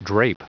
Prononciation du mot drape en anglais (fichier audio)
Prononciation du mot : drape